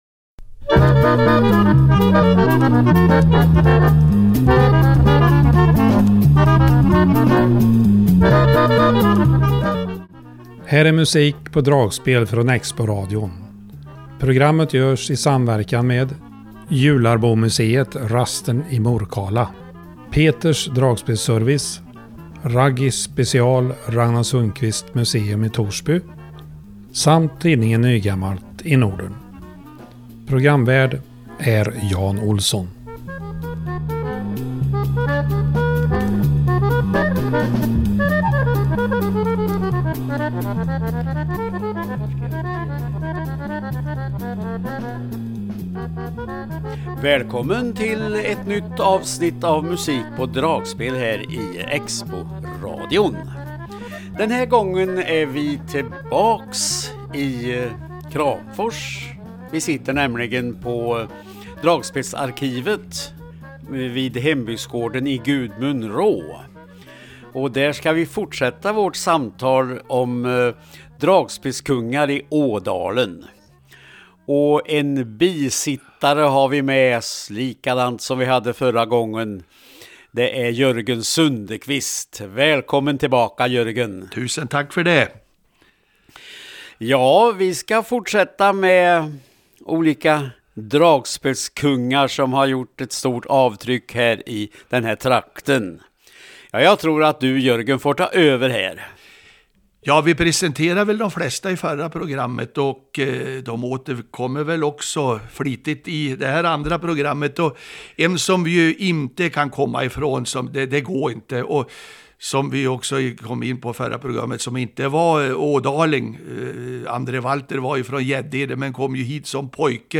musik_pa_dragspel_februari_2025.mp3